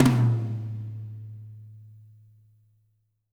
-TOM 2O   -R.wav